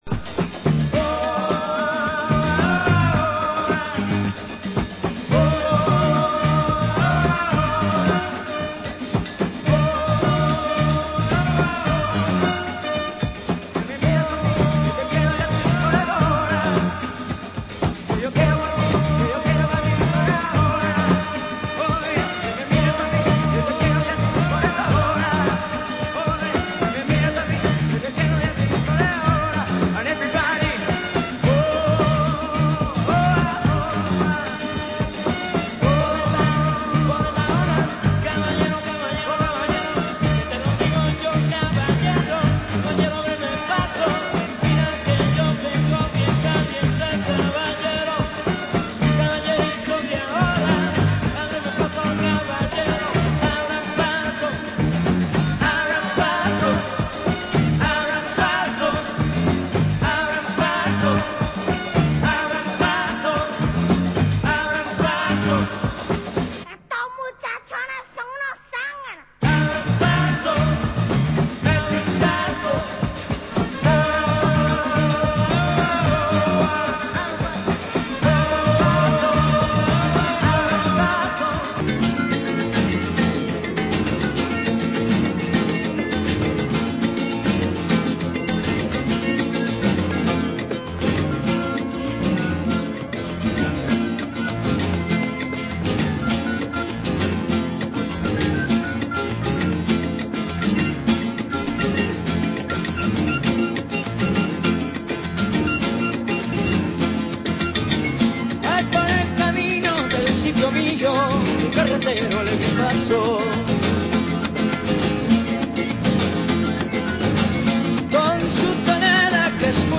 Genre: Balearic